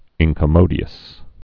(ĭnkə-mōdē-əs)